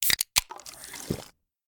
SBobDrink.ogg